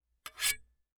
Metal_26.wav